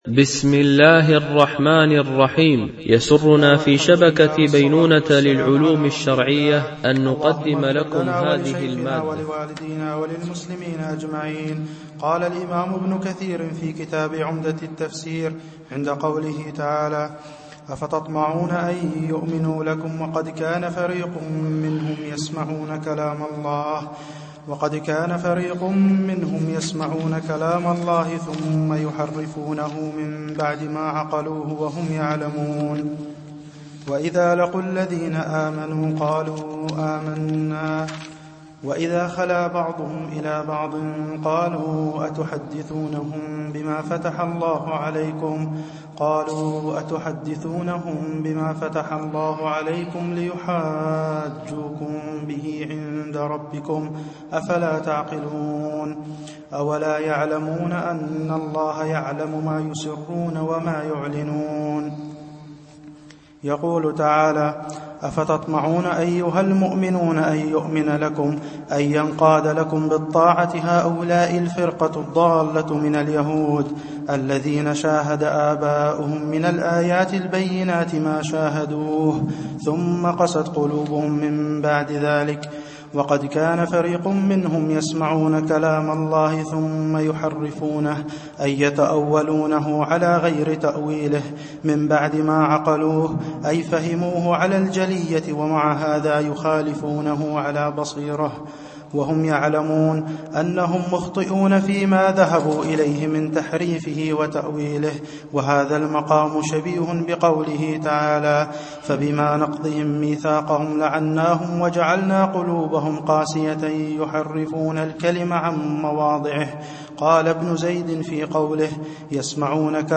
صوتية الدرس